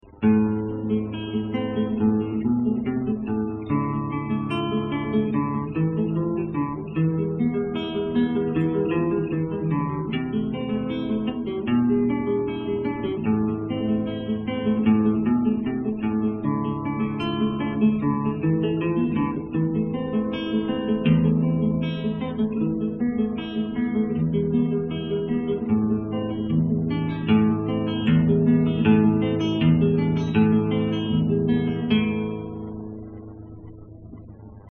[mp3] Allegro, Carcassi - Guitare Classique
Le son est bon, pas de soucis
Petit conseille fait attention au tempo, tu accélères beaucoup
une petite tentative de mixage... Vite fait!
C'est vrai que tu accélères un peu mais c'est quand même la partie que je préfères.